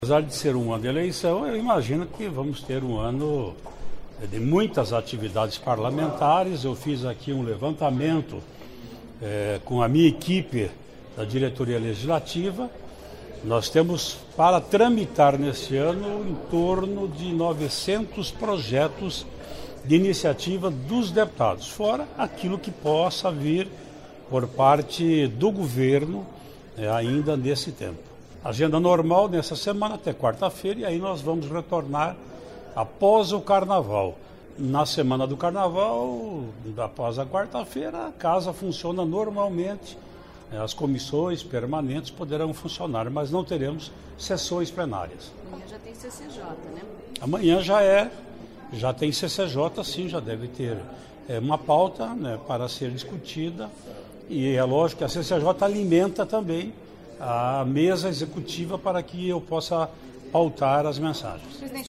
Ouça entrevista coletiva do presidente da Assembleia Legislativa, deputado Ademar Traiano (PSDB), onde ele afirma: "Serão mais de 900 as propostas de lei de iniciativa dos parlemntares em 2018". Iso representa mais que o dobro apresentado em 2017.